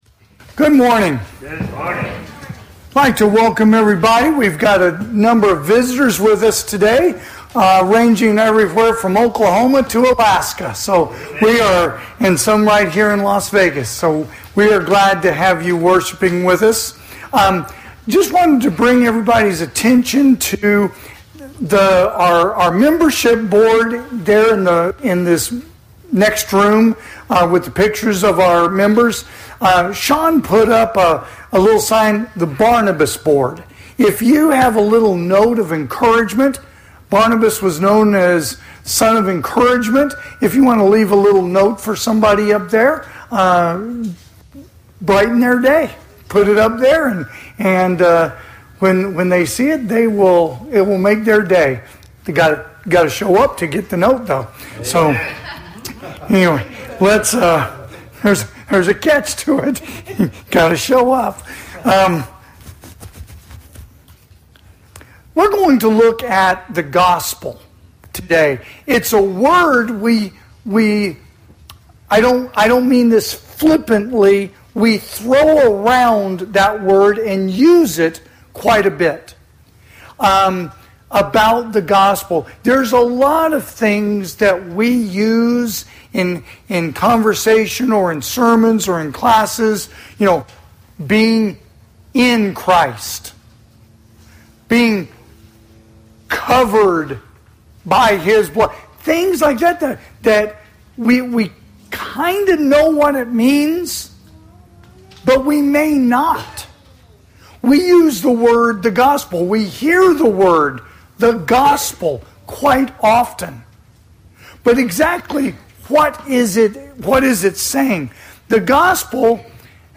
AM Worship
Sermons